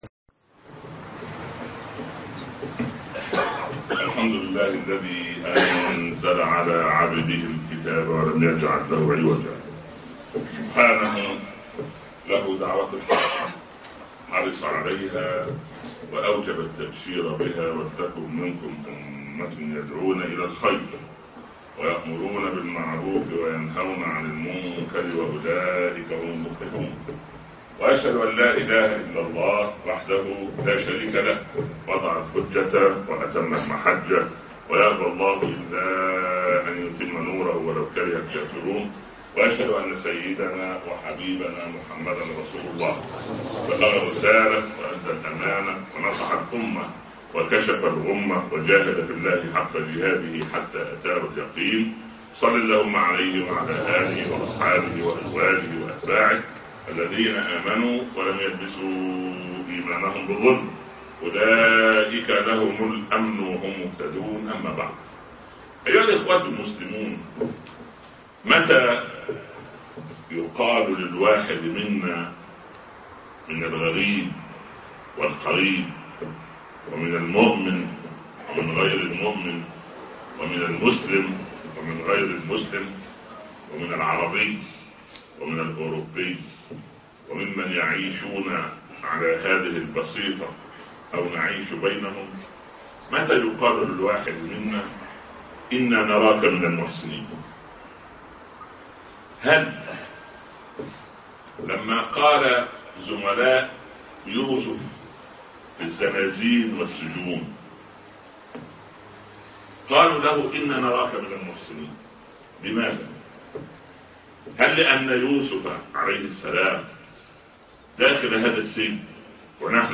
انا نراك من المحسنين (15/5/2015) خطب الجمعه - الشيخ عمر بن عبدالكافي